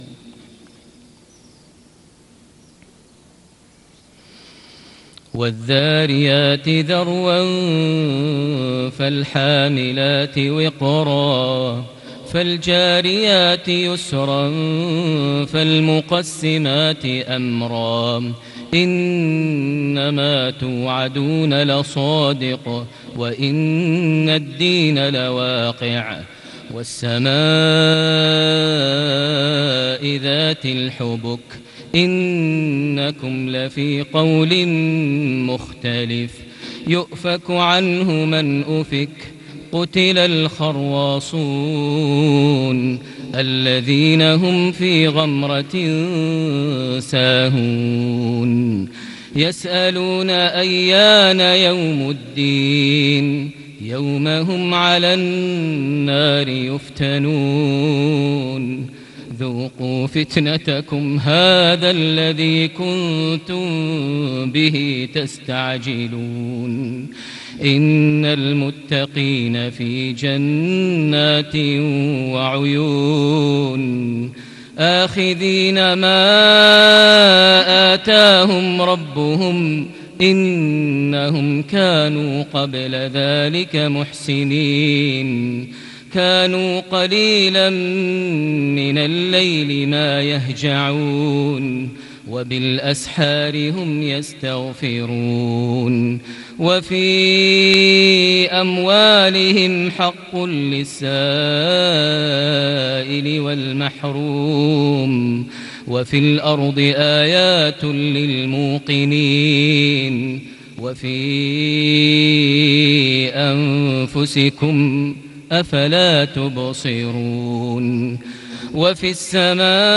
صلاة الفجر ١٧ ذو القعدة ١٤٣٨هـ سورة الذرايات > 1438 هـ > الفروض - تلاوات ماهر المعيقلي